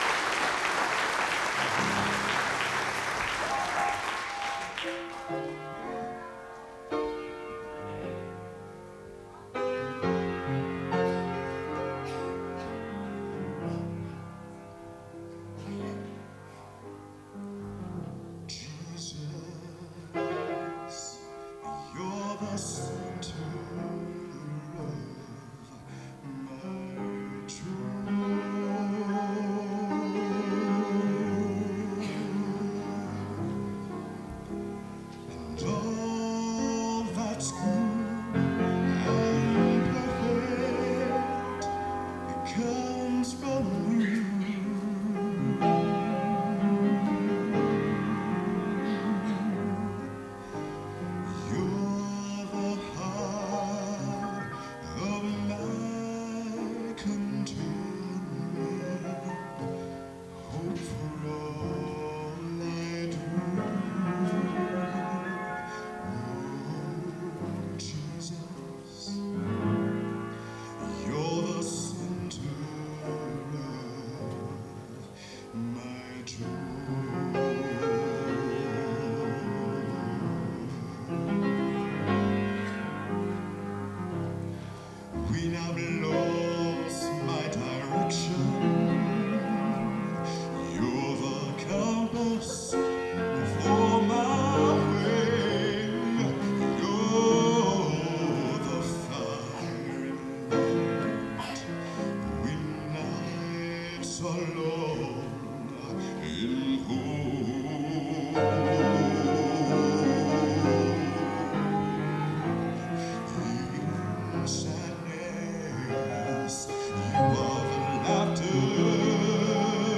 Genre: Gospel | Type: Featuring Hall of Famer |Specialty